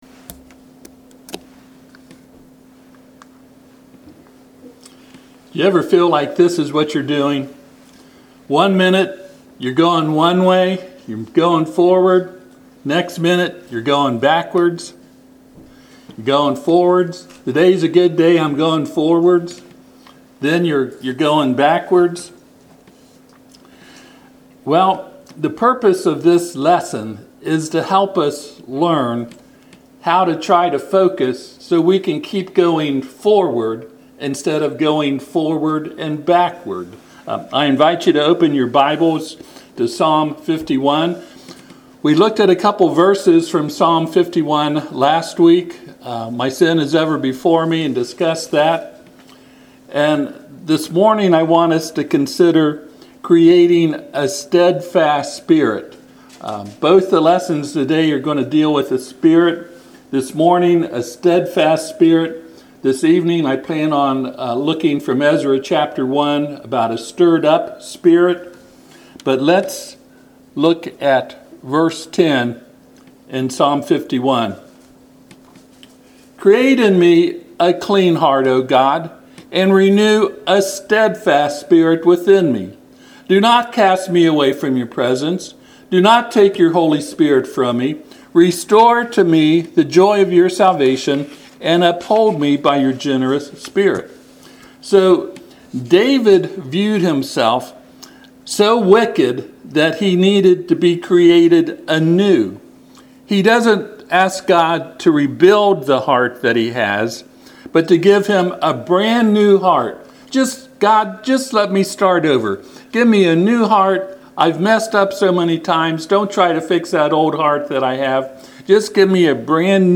Passage: Psalm 51:10-14 Service Type: Sunday AM